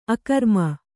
♪ akarma